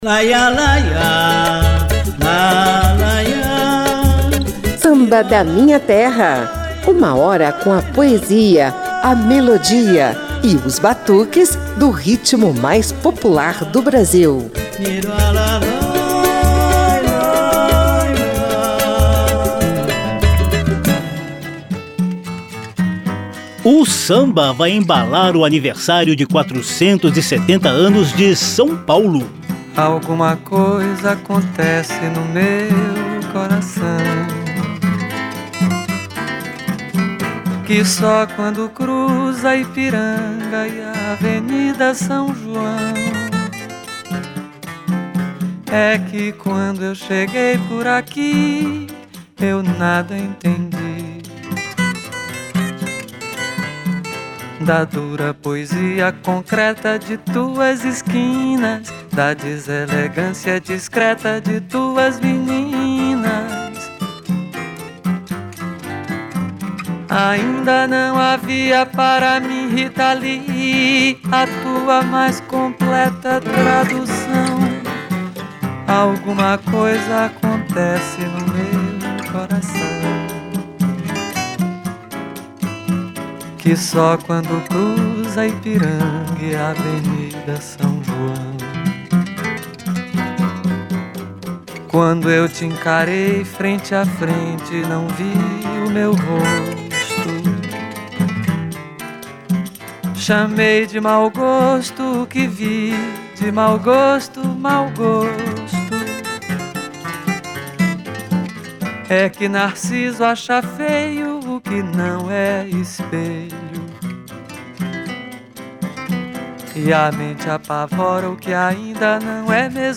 São Paulo será decantada em versos, prosas e batuques no Samba da Minha Terra. A cidade completa 470 anos em 25 de janeiro e ganha de presente uma edição inteira dedicada à história do samba de raiz por lá. O quadro “papo de samba” recorre aos mestres Geraldo Filme e Oswaldinho da Cuíca para retratar as origens do batuque de zabumba vindo das festas de Pirapora do Bom Jesus, na parte mais rural da região metropolitana.